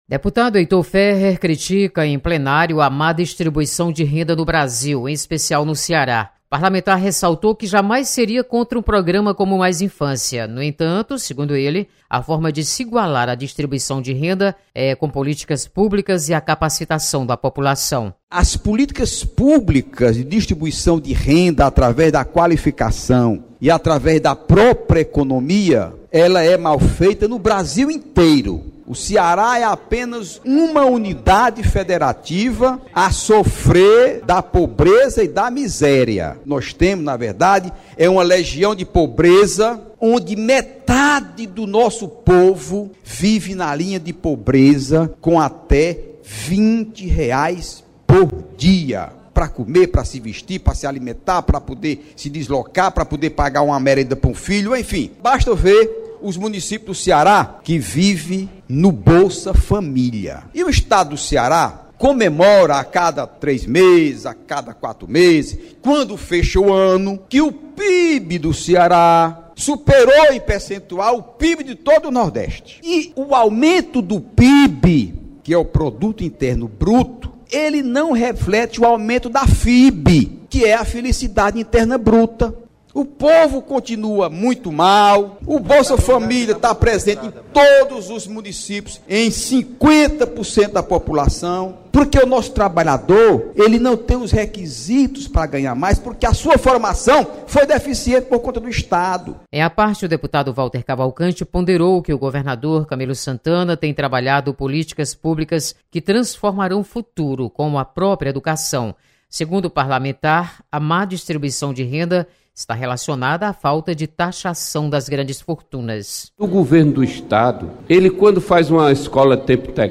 Deputados comentam sobre pobreza no Ceará.